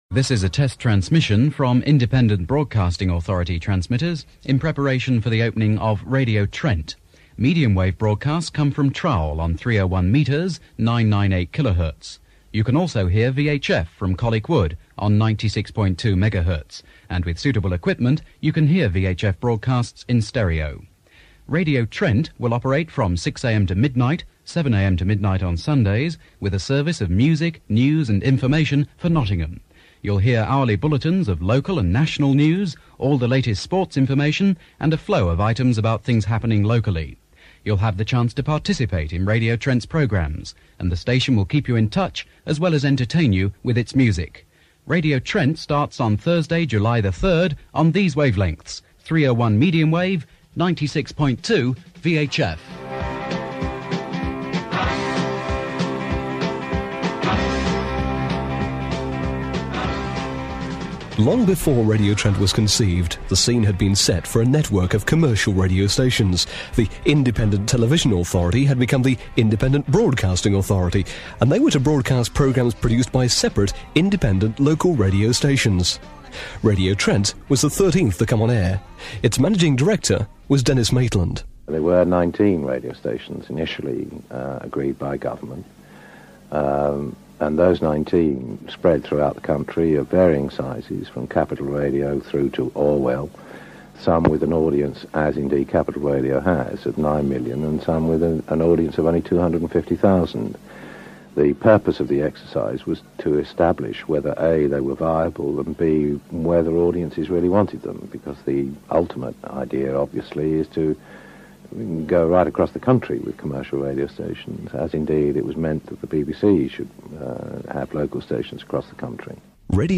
I produced this documentary in 1985, as Trent celebrated ten years on air.